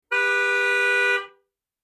Download Horn sound effect for free.
Horn